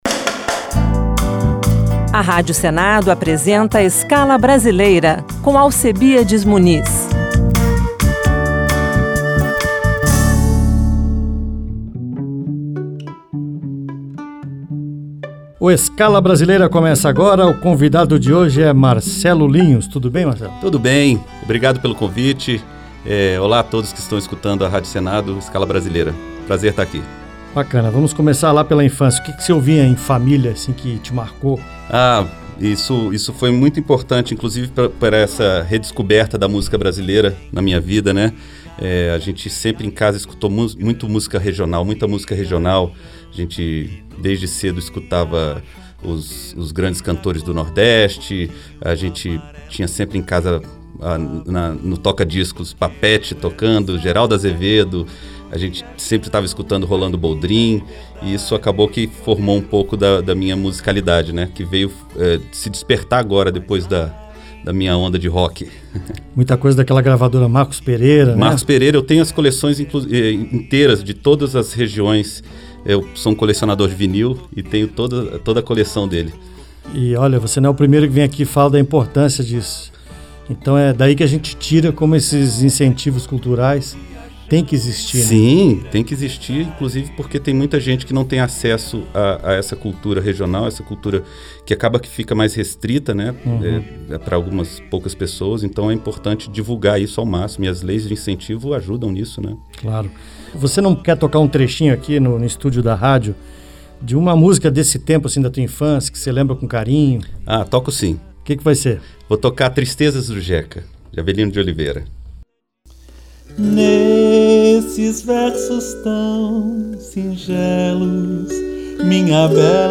Ouviremos: Tristeza do Jeca e Pagode de Viola (gravadas no estúdio da Rádio senado).